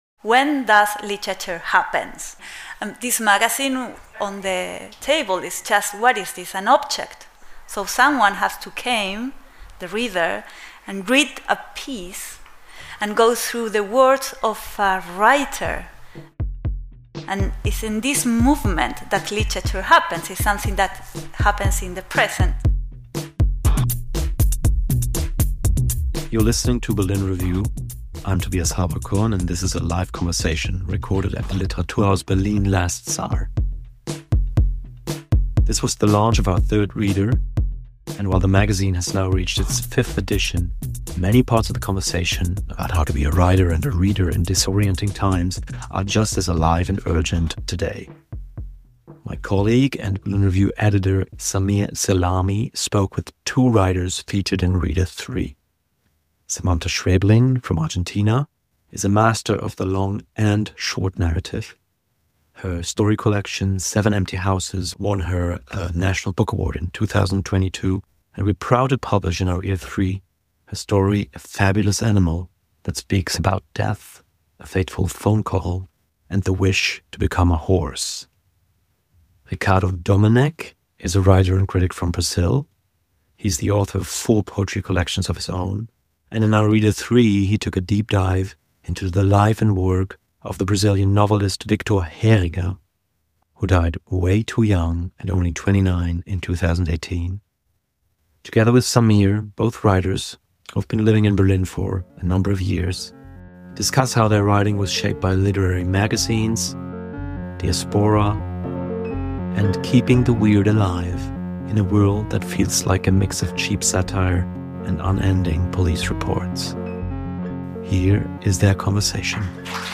On the launch night for Berlin Reader 3
sat down with two Berlin writers